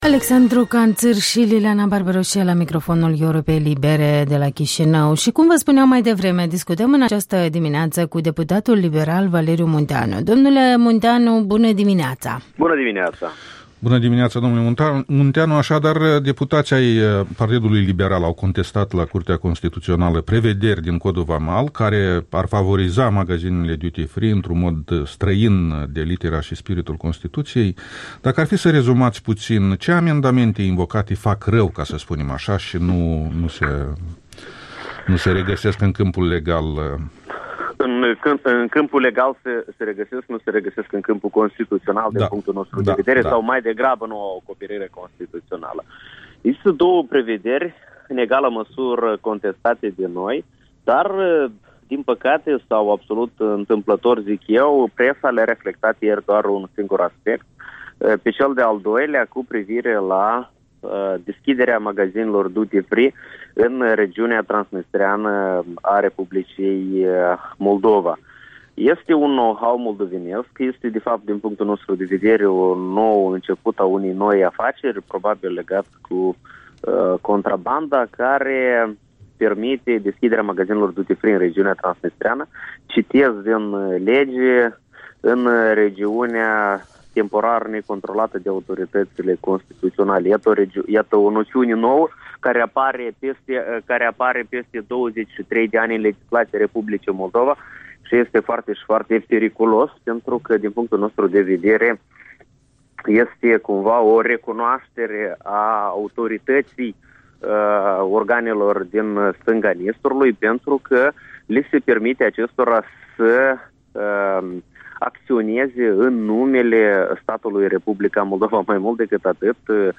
Interviul dimineții cu un deputat liberal despre dispoziții contestate ale Codului vamal.